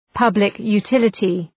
Προφορά
public-utility.mp3